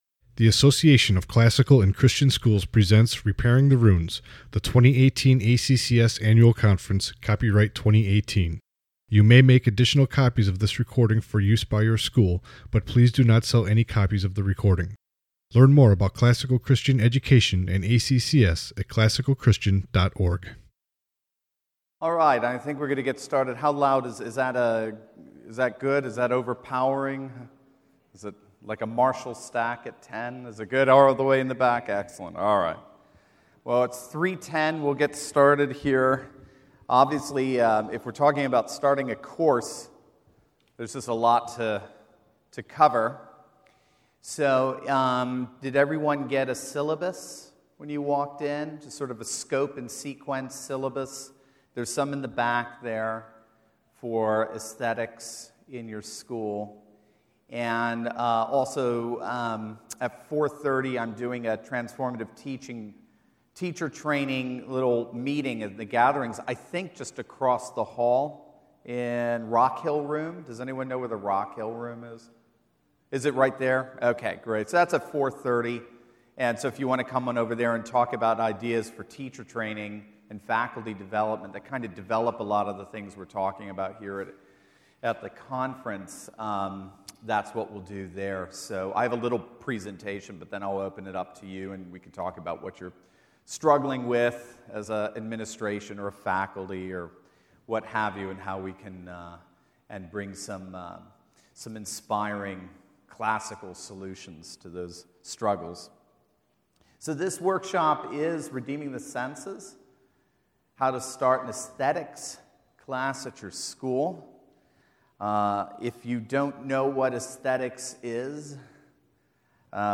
2018 Workshop Talk | 1:07:51 | All Grade Levels, Art & Music, Virtue, Character, Discipline
Additional Materials The Association of Classical & Christian Schools presents Repairing the Ruins, the ACCS annual conference, copyright ACCS.